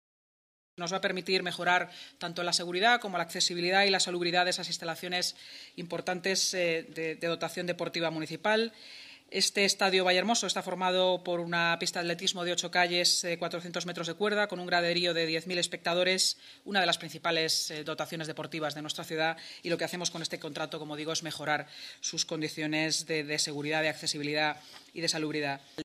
El Ayuntamiento de Madrid ha dado luz verde a un nuevo contrato de servicios para el mantenimiento integral del Estadio Vallehermoso. La vicealcaldesa y portavoz municipal, Inma Sanz, ha explicado en rueda de prensa que el nuevo contrato, aprobado hoy en Junta de Gobierno, con un importe de un millón de euros a ejecutar en los próximos dos años, permitirá mejorar la seguridad, accesibilidad y salubridad de las instalaciones de esta dotación deportiva municipal.